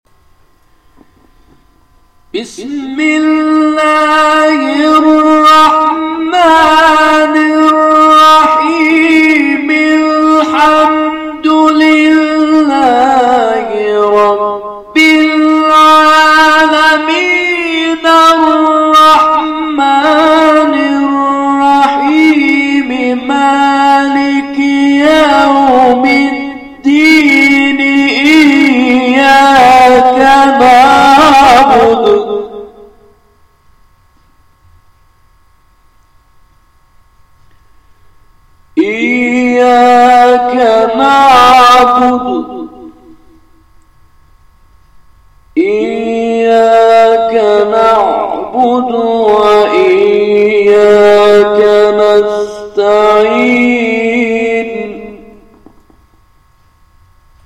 گروه شبکه اجتماعی: مقاطعی از تلاوت قاریان ممتاز و بین‌المللی کشور که به‌تازگی در شبکه اجتماعی تلگرام منتشر شده است، می‌شنوید.